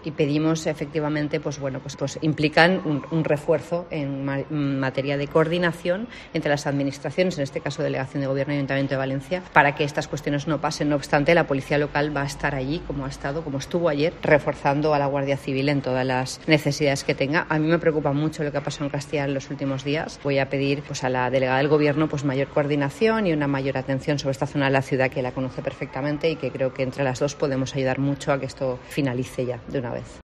Catalá se ha pronunciado de este modo durante la visita que ha realizado al colegio municipal de Benimaclet por el inicio del curso escolar, preguntada por los altercados que han tenido lugar en Castellar.